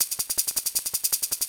Shaker 05.wav